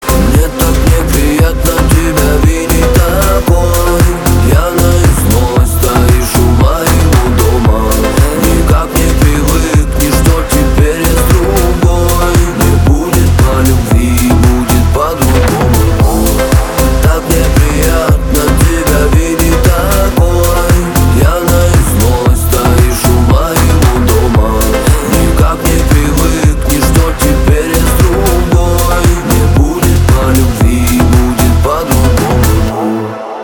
• Качество: 320, Stereo
мужской вокал
deep house
dance
Зажигательный трек